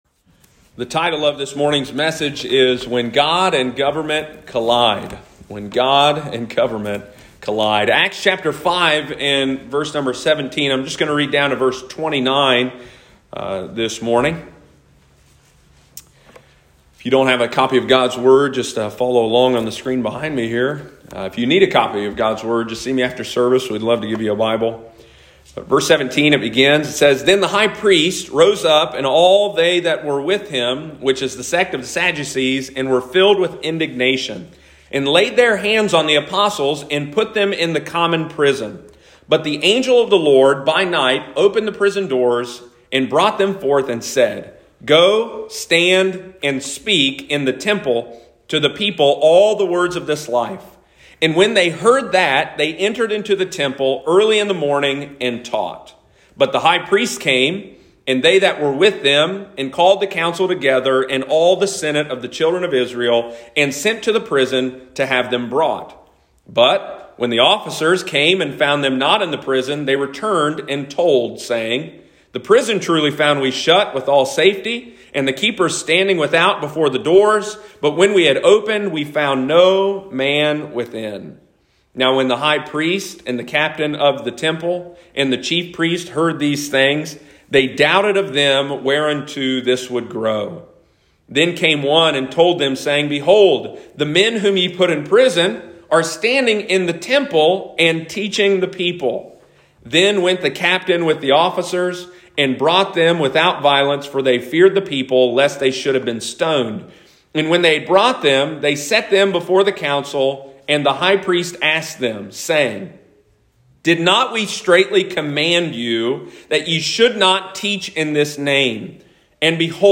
When God and Government Collide – Lighthouse Baptist Church, Circleville Ohio
We understand that as believers we should submit to the powers ordained by God but how are Christians to respond when God and government disagree? Sunday morning, March 20, 2022.